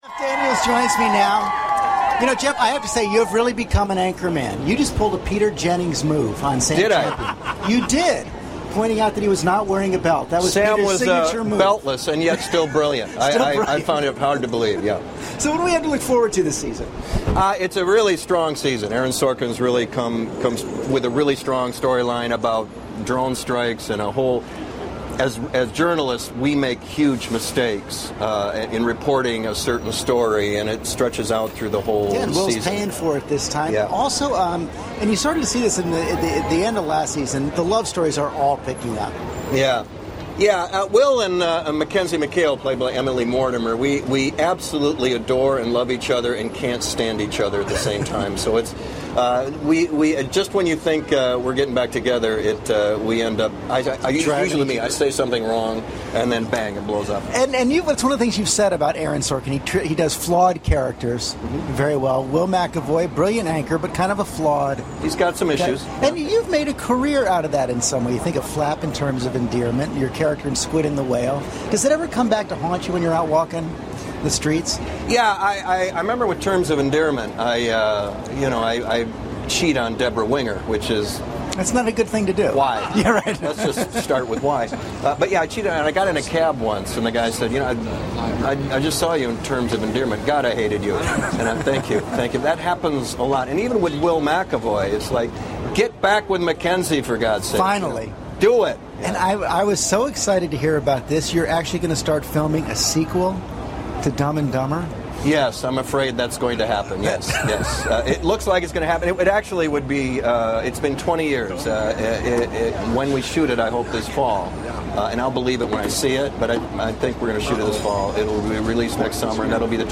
访谈录 2013-07-15&07-17 老戏骨杰夫·丹尼尔斯专访 听力文件下载—在线英语听力室